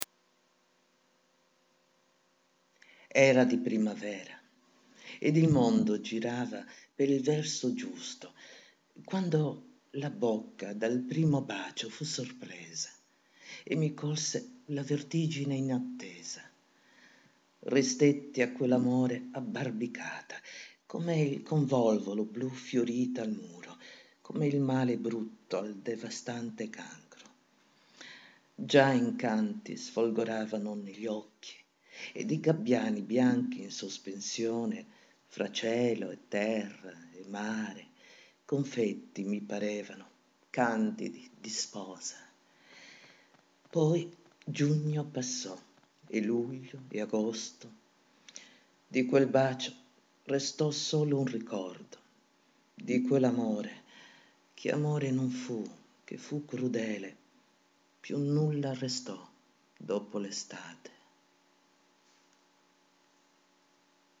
ascoltare la poesia recitata dall'attrice e regista teatrale